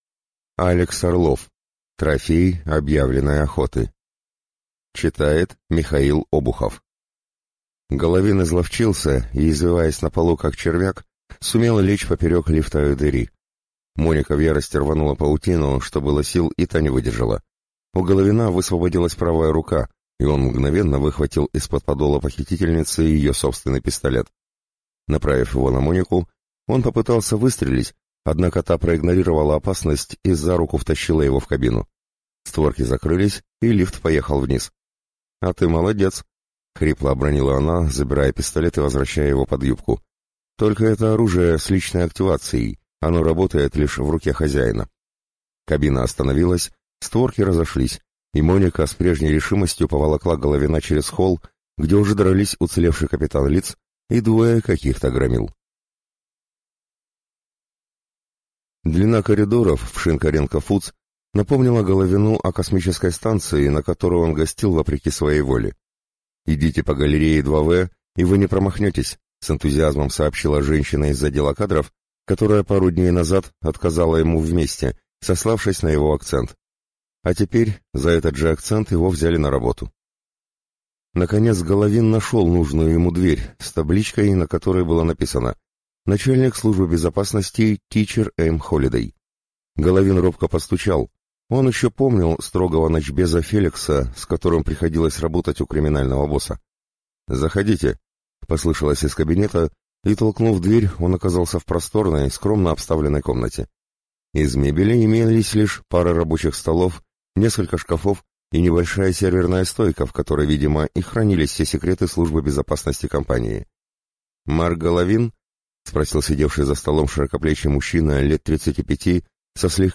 Аудиокнига Трофей объявленной охоты | Библиотека аудиокниг